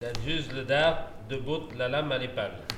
Mémoires et Patrimoines vivants - RaddO est une base de données d'archives iconographiques et sonores.
Il aiguise la faux ( selon l'une des trois façons d'aiguiser )